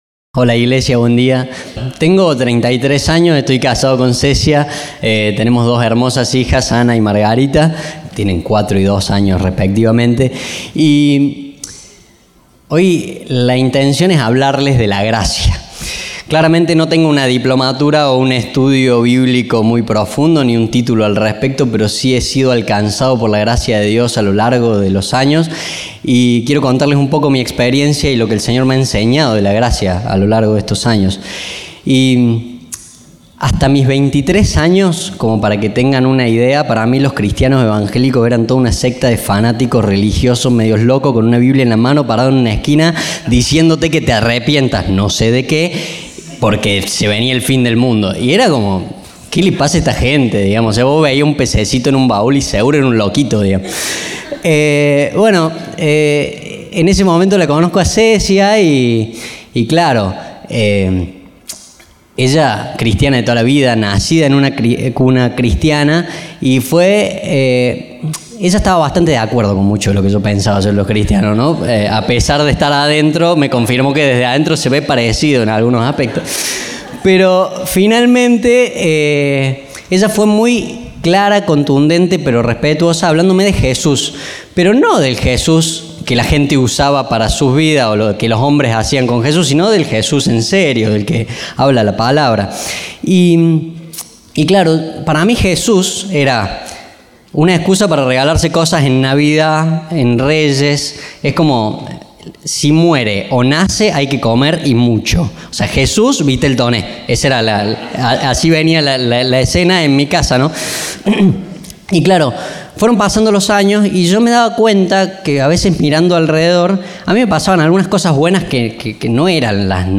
Compartimos el mensaje del Domingo 5 de Octubre de 2025